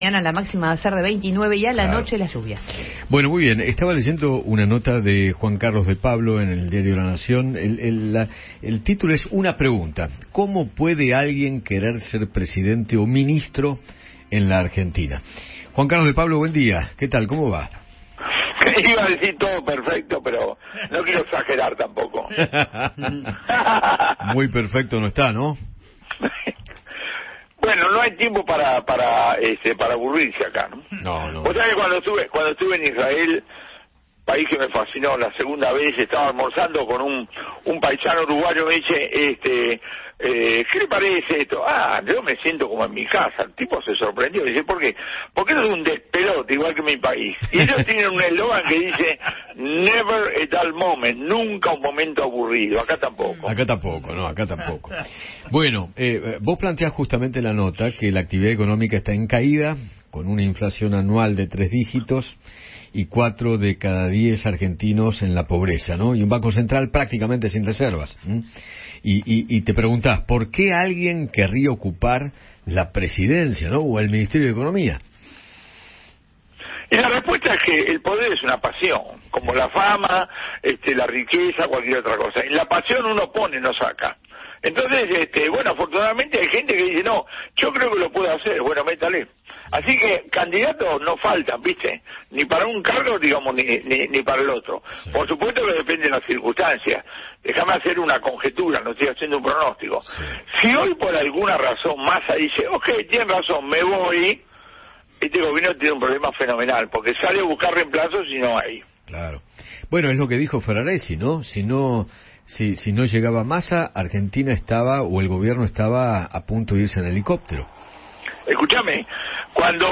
El economista Juan Carlos De Pablo dialogó con Eduardo Feinmann sobre la gestión de Sergio Massa y analizó la situación económica del país.